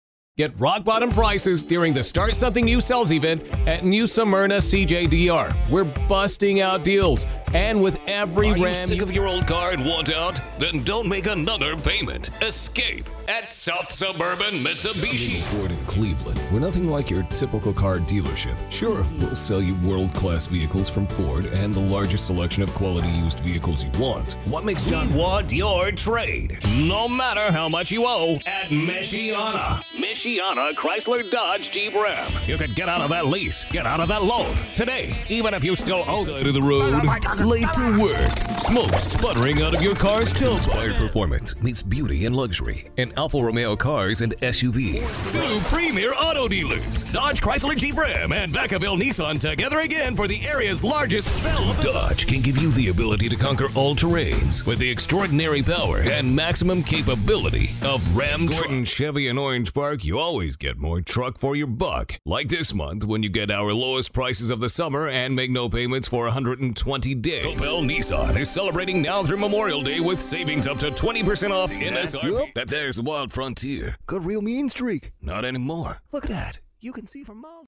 Since 2001, I’ve worked nationally and internationally as a voice over talent, delivering broadcast-ready reads for TV, radio, automotive, and documentary projects from a professional studio.
Automotive
Middle Aged